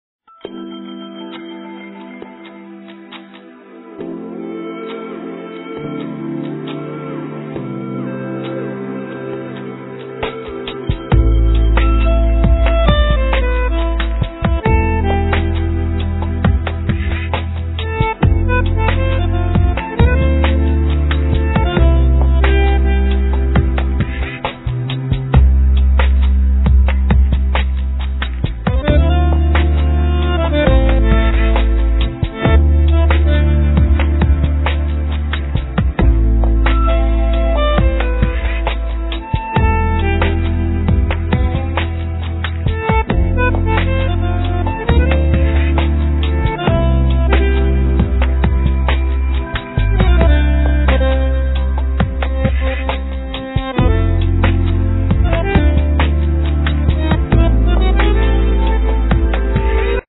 Bandoneon, Marimba, TR-808, Bongo, Drums
Bass
Synthesizer
Trumpet
Vocals